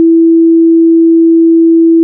sine.wav